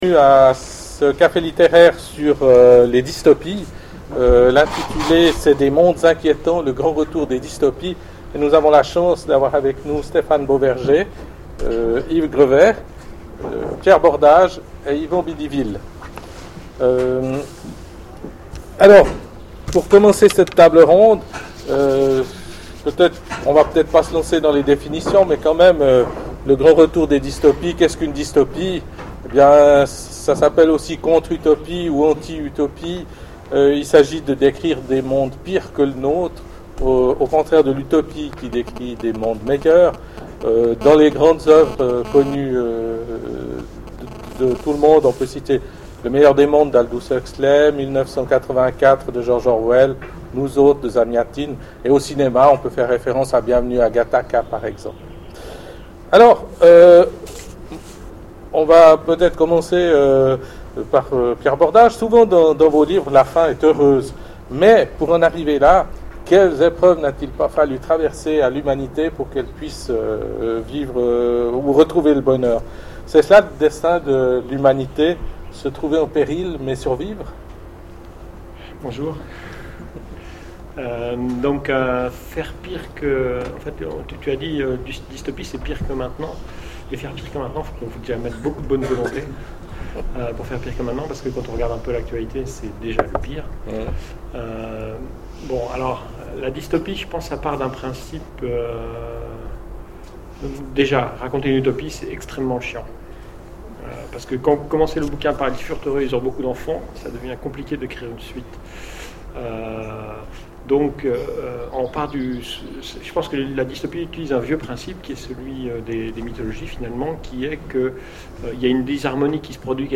Imaginales 2013 : Conférence Des mondes inquiétants
Conférence